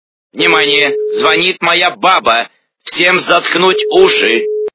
» Звуки » Люди фразы » Михаил Галустян - Внимание! звонит моя баба
При прослушивании Михаил Галустян - Внимание! звонит моя баба качество понижено и присутствуют гудки.